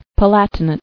[Pa·lat·i·nate]